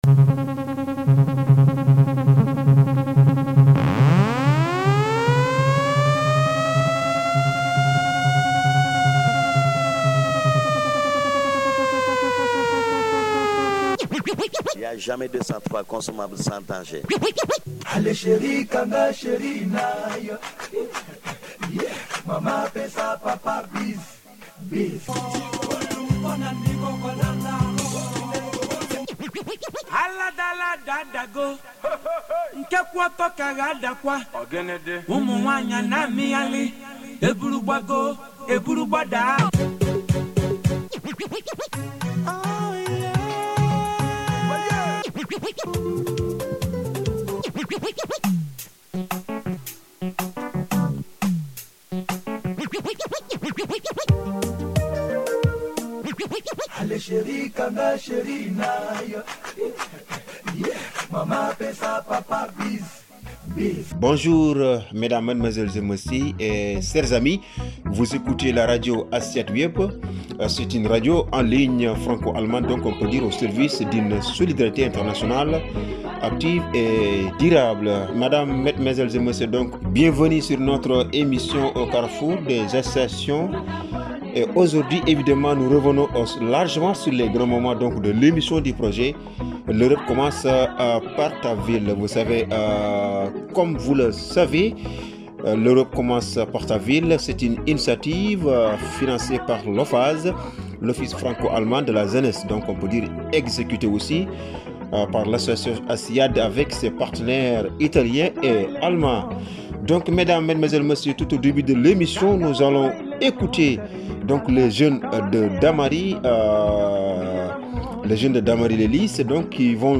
En attendant cet aboutissement, des jeunes issus de milieux très divers (lycéens, jeunes professionnels, mais aussi jeunes vivant dans des centres de réfugiés) ont créé une émission de radio en ligne où les lettres de doléances rédigées lors des rencontres ont pu être diffusées.